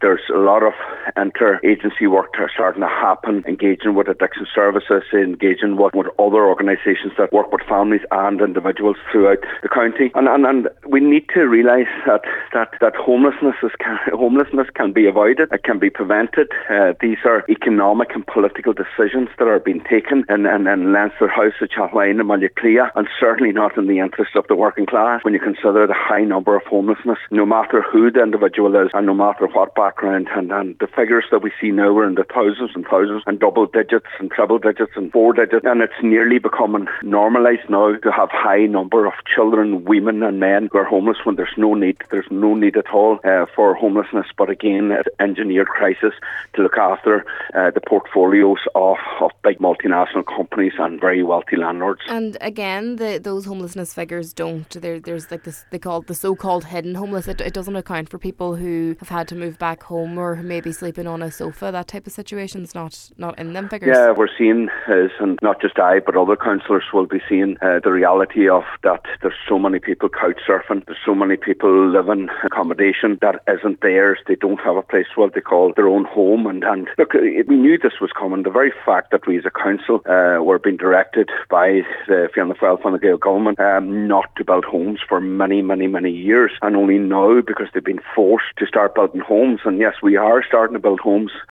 Cllr Mac Giolla Easbuig says these situations are visible to councilors on the ground, highlighting the need for more action: